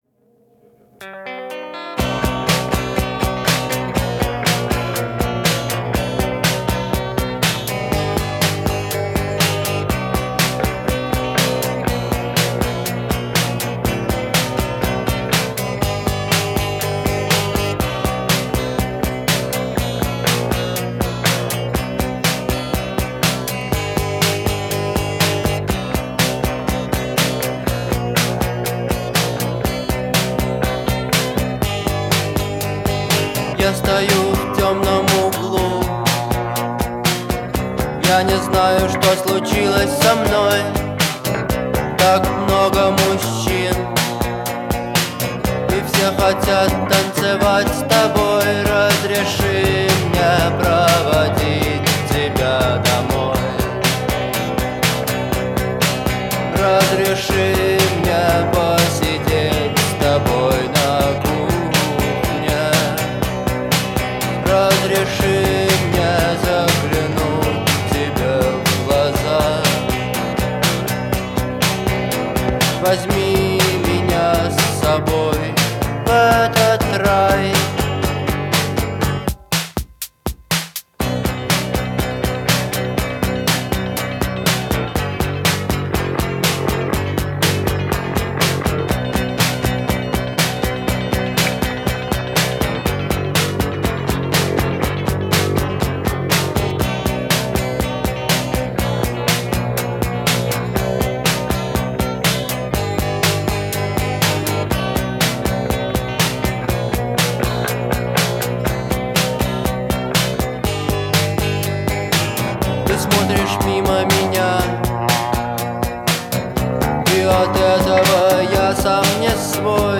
выполненная в жанре рок.
создавая атмосферу интимности и размышлений.